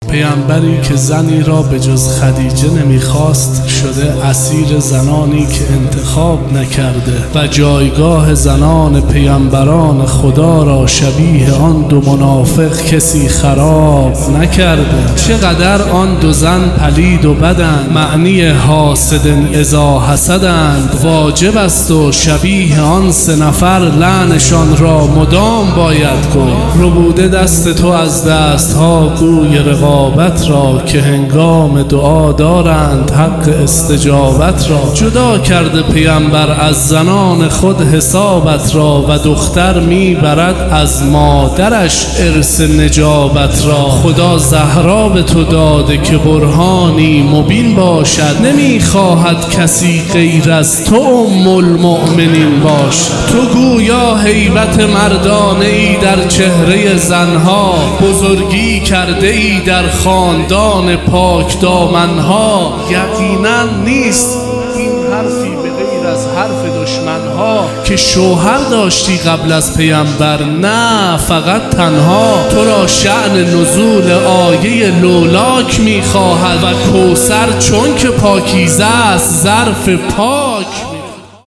هیئت ام ابیها قم | شهادت حضرت خدیجه سلام الله علیها 1401